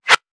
metahunt/weapon_bullet_flyby_13.wav at 84a4c88435ec9cf2ad6630cab57ea299670b57e2
weapon_bullet_flyby_13.wav